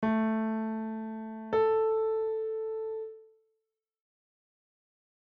On the piano, play the following:
A - then up an octave - play the next A